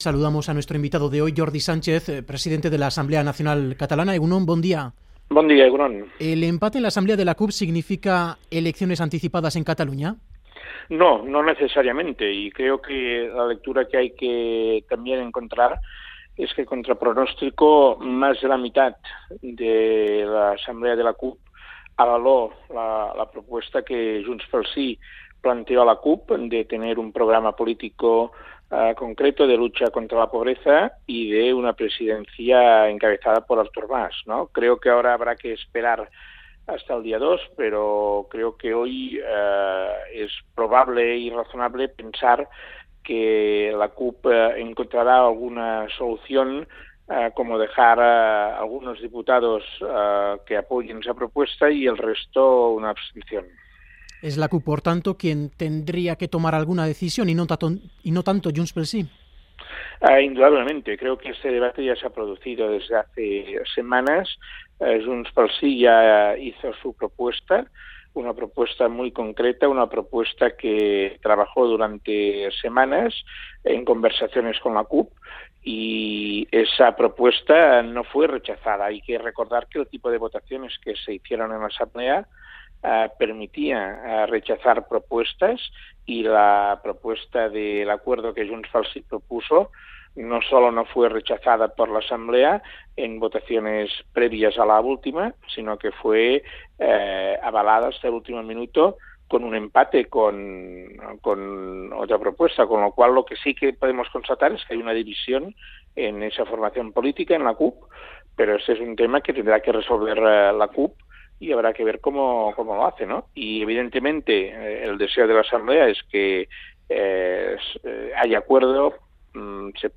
Entrevista a Jordi Sanchez, presidente de la Asamblea Nacional Catalana, en el programa ‘Boulevard’, en Radio Euskadi.